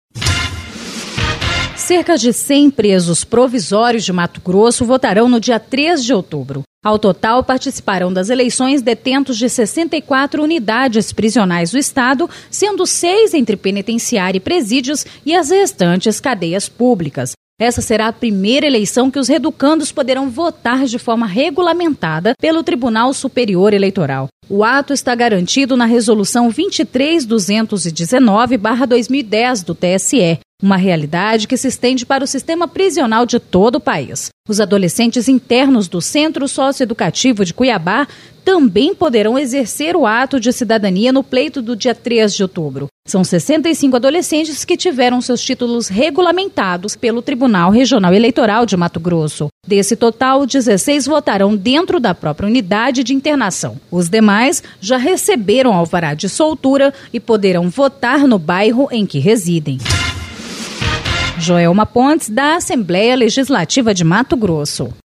Programa diário com reportagens, entrevistas e prestação de serviços
Notícias da Assembleia Legislativa do Mato Grosso